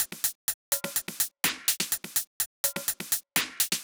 Drumloop 125bpm 04-B.wav